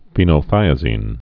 (fēnō-thīə-zēn, -nə-)